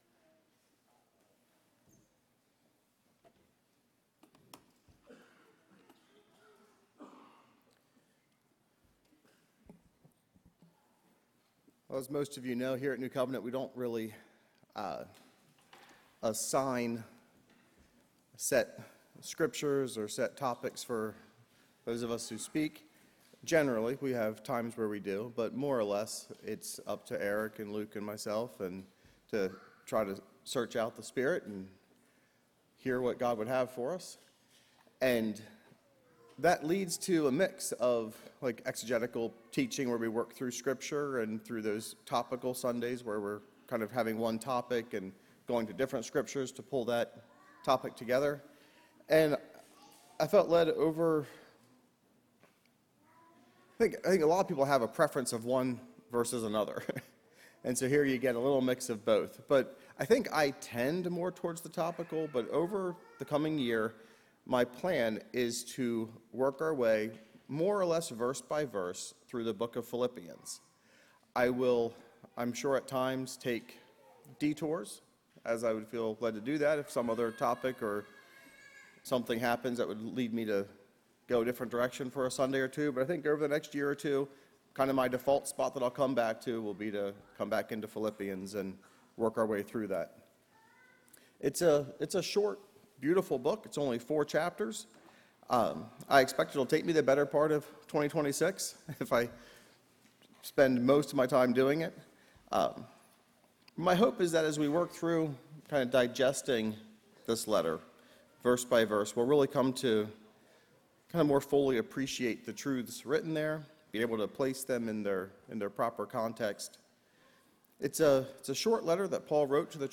A message from the series "Sunday Morning - 10:30."